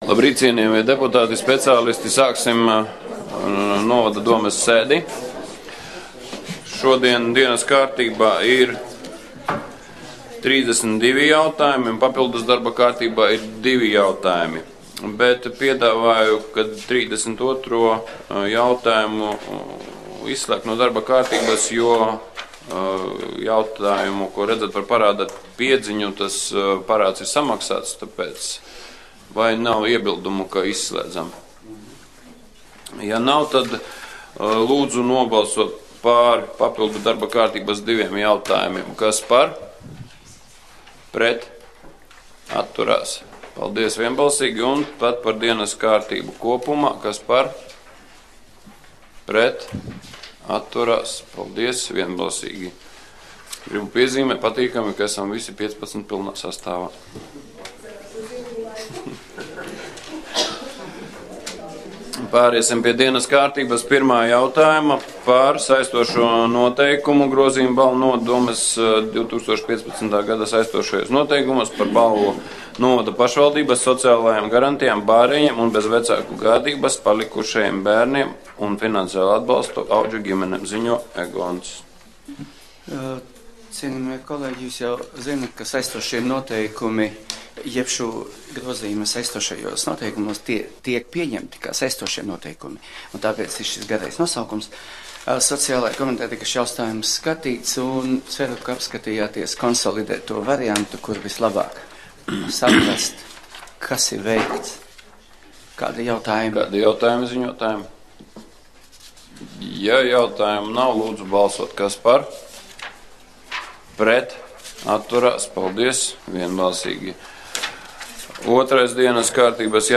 8. februāra domes sēde